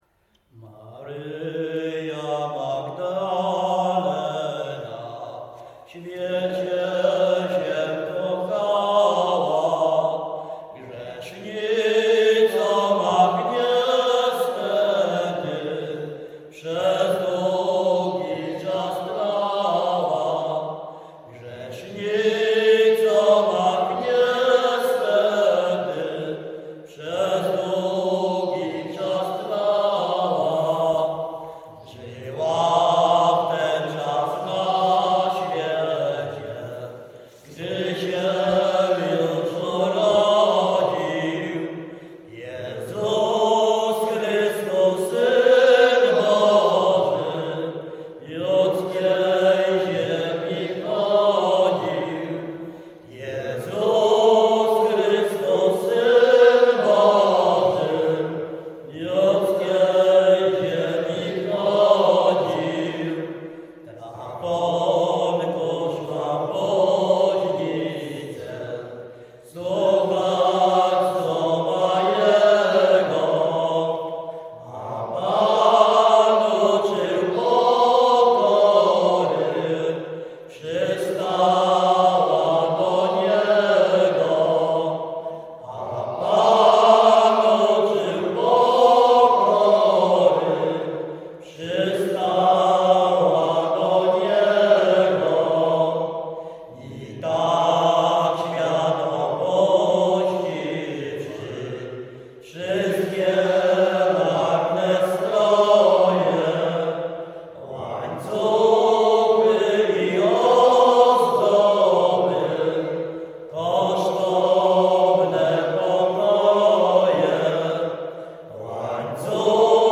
Śpiewacy z Ruszkowa Pierwszego
Wielkopolska, powiat kolski, gmina Kościelec, wieś Ruszków Pierwszy
podczas zgromadzenia śpiewaczego w kaplicy w Ruszkowie
Array o świętych nabożne katolickie pogrzebowe